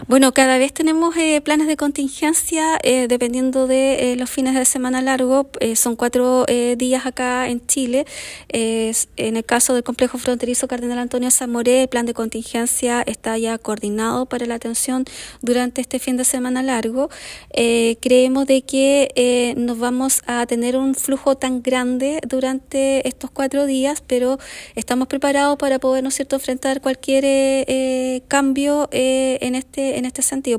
Según señaló la Delegada Presidencial Provincial, Claudia Pailalef, el operativo incluye controles de tránsito y medidas adicionales en puntos estratégicos, como el complejo fronterizo Cardenal Samoré, donde se reforzarán los servicios para manejar un posible aumento en el flujo de vehículos que se espera crucen durante estos días.